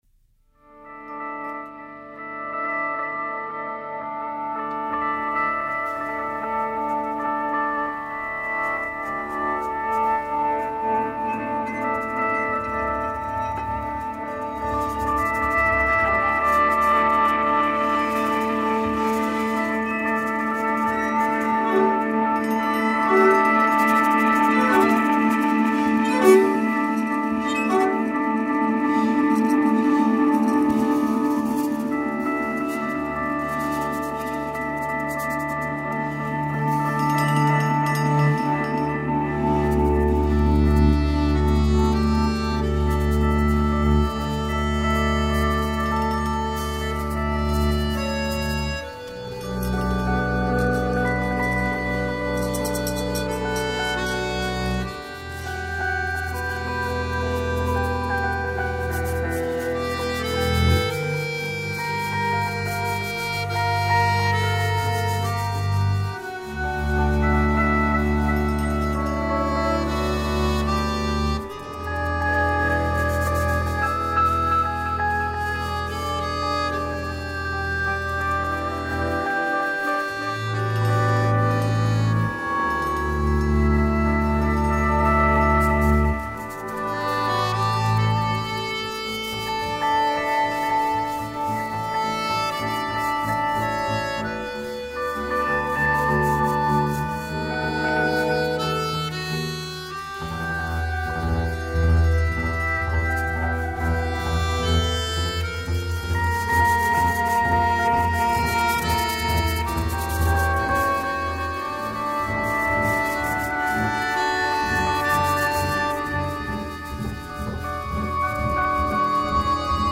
double bass
guitars and effects
drums and percussion
trumpet and voice